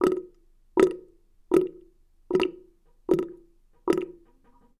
bathroom-sink-15
bath bathroom bubble burp click drain dribble dripping sound effect free sound royalty free Sound Effects